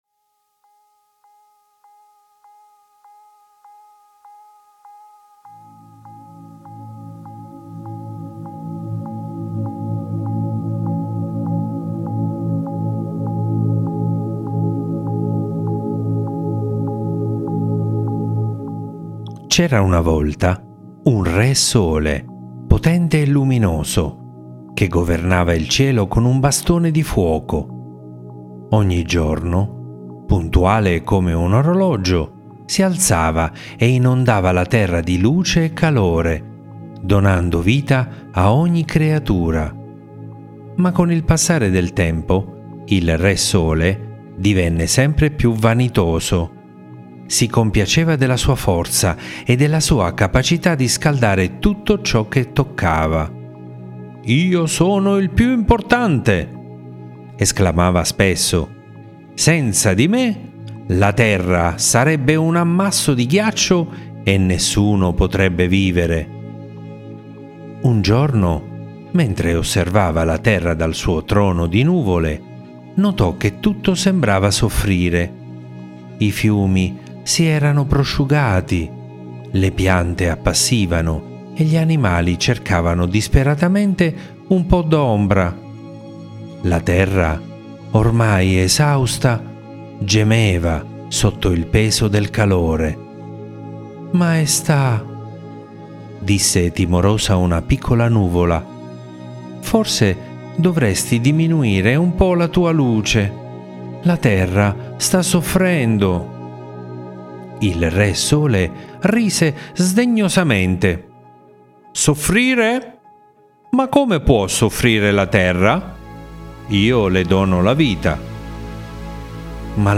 Le favole della buonanotte